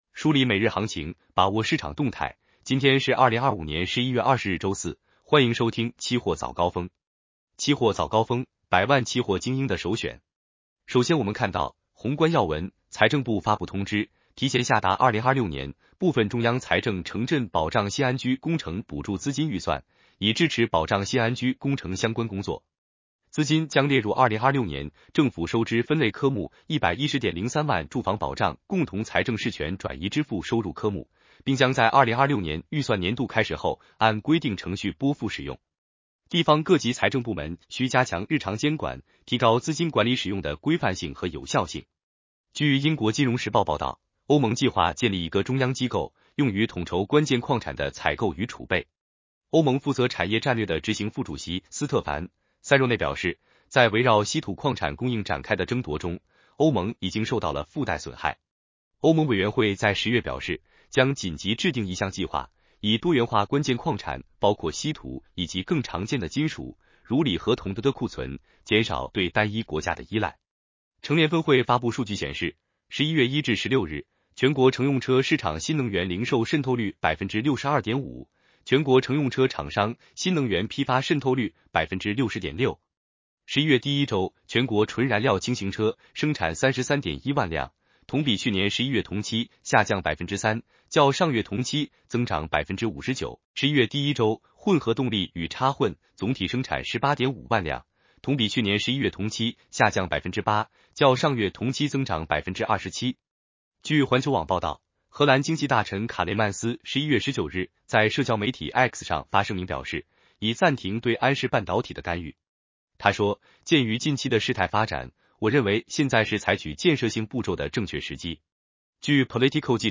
期货早高峰-音频版
期货早高峰-音频版 男生普通话版 下载mp3 热点导读 1.